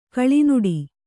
♪ kaḷinuḍi